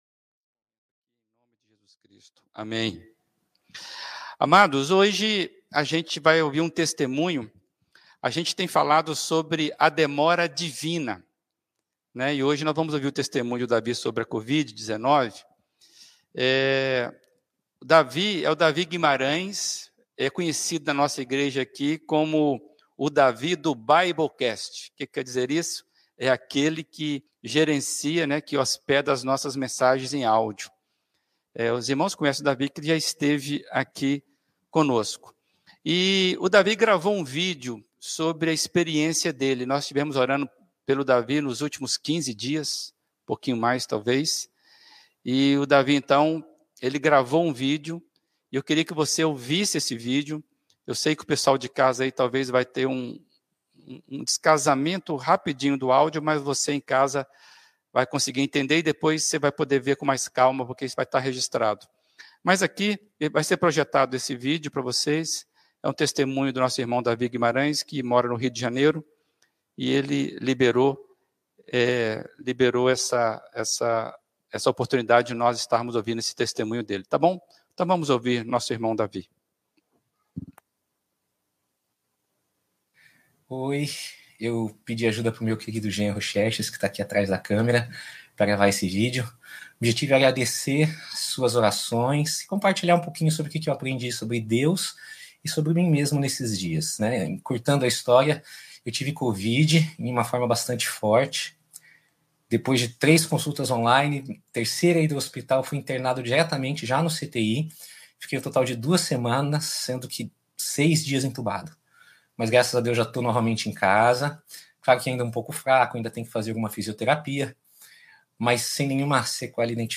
Mensagem
na Primeira Igreja Batista de Brusque